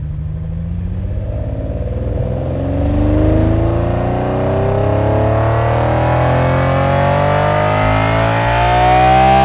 v12_08_accel.wav